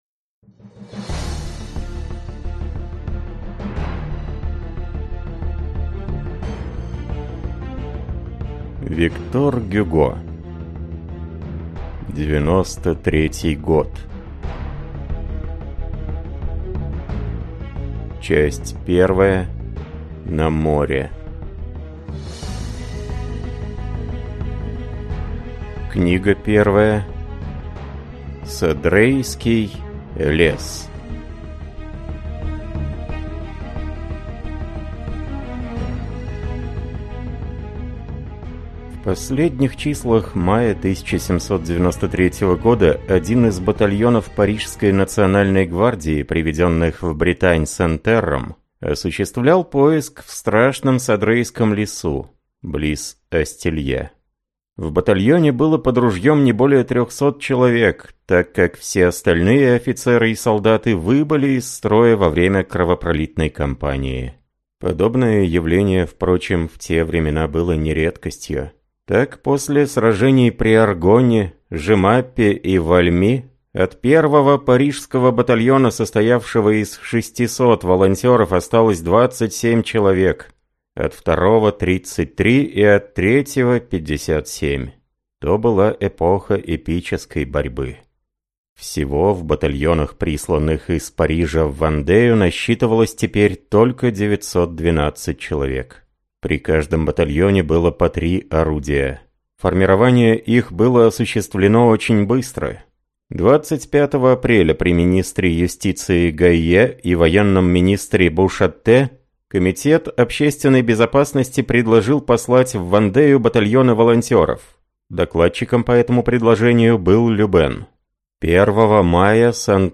Аудиокнига Девяносто третий год | Библиотека аудиокниг